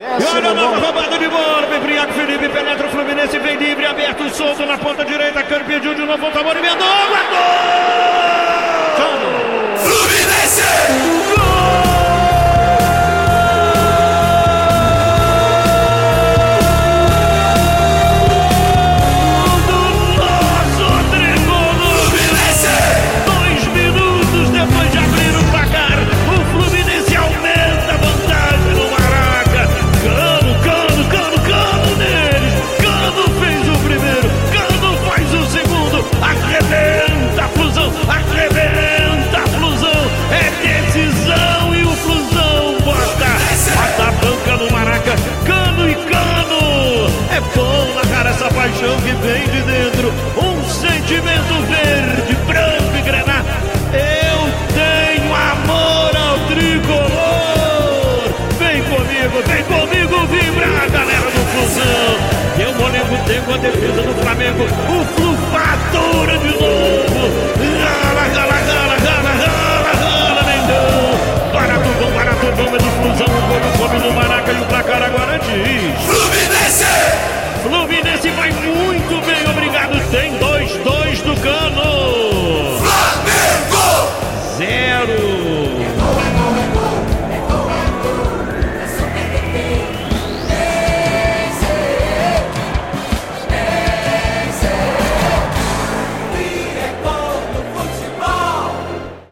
Ouça os gols da vitória do Fluminense sobre o Flamengo pela final do Carioca com a narração de Luiz Penido